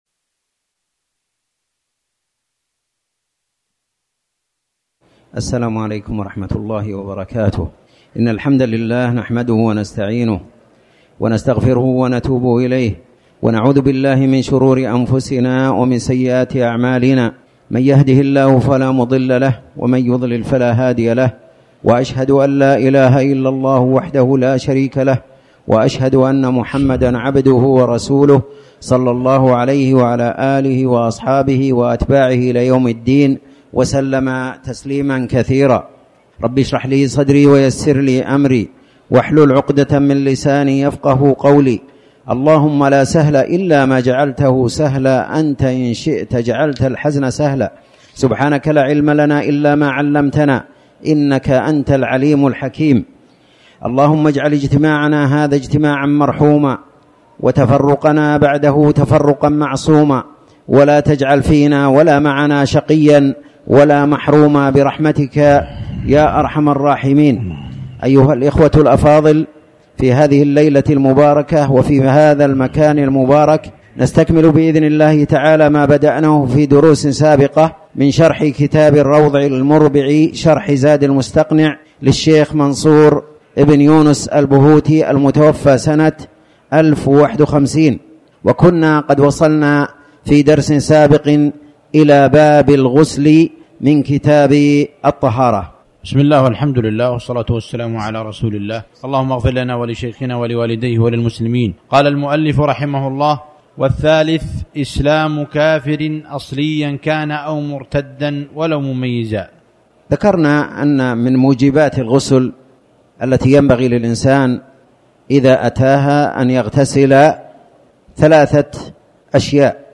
تاريخ النشر ١٨ ربيع الأول ١٤٤٠ هـ المكان: المسجد الحرام الشيخ